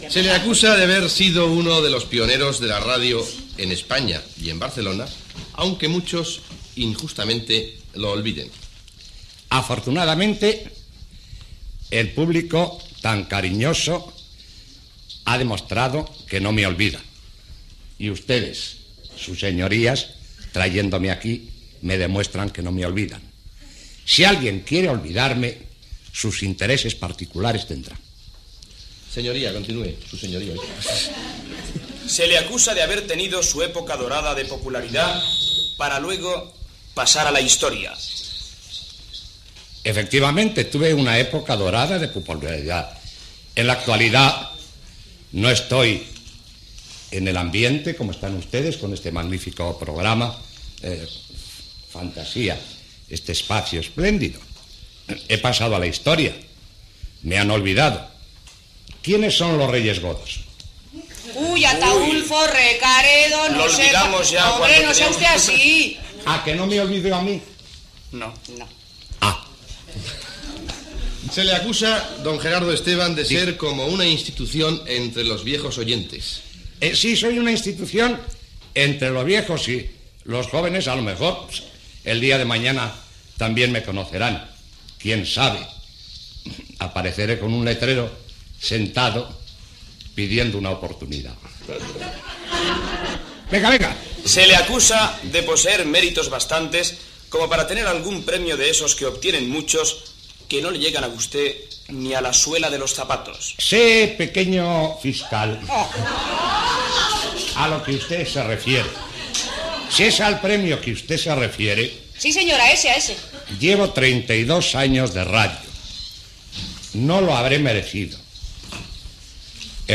Entreteniment
"Fantasía" era un programa de varietats, cara al públic, de la tarda dels dissabtes. Es feia des de l'Estudi 1 del Passeig de Gràcia 1 de Barcelona.